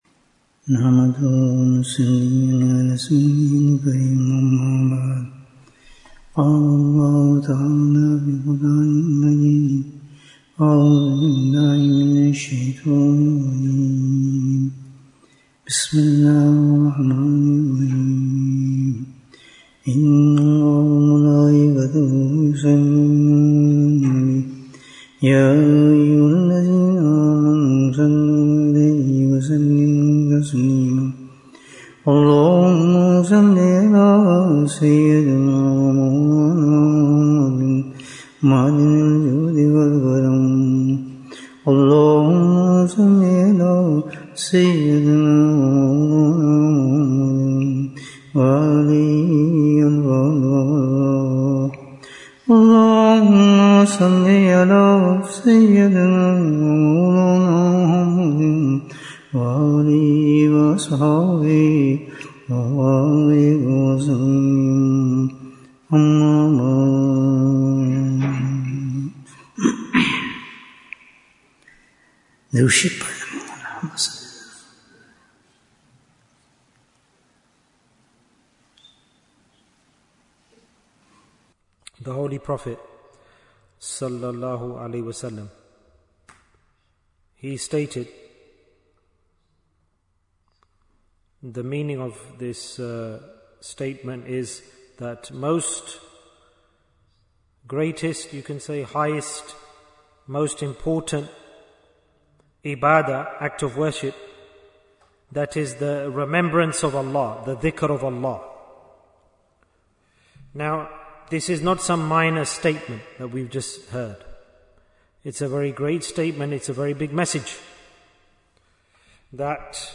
Why is Dhikr Important? Bayan, 51 minutes24th July, 2025